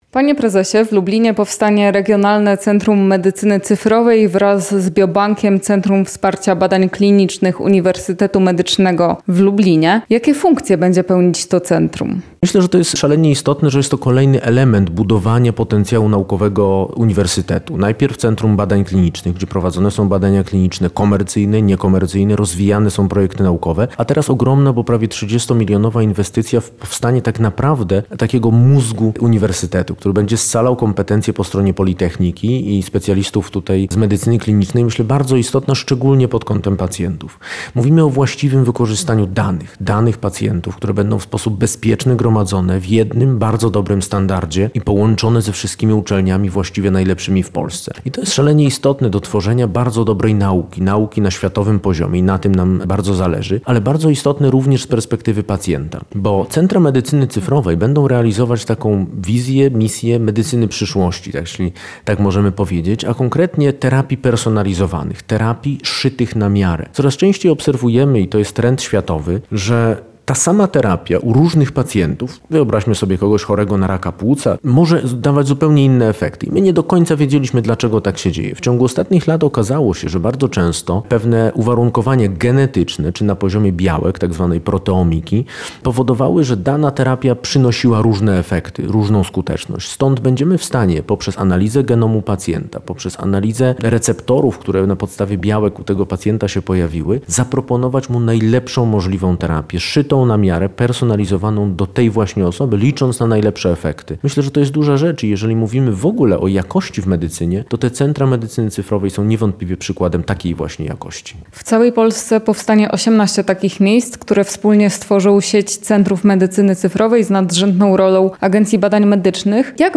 O medycynie cyfrowej w Polsce opowiada prezes Agencji Badań Medycznych, dr hab. n. med. Radosław Sierpiński w rozmowie